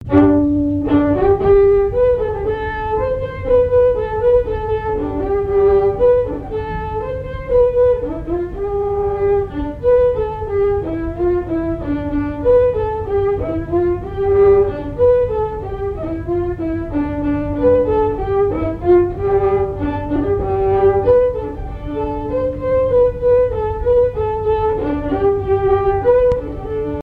danse : ronde
Airs à danser aux violons et deux chansons
Pièce musicale inédite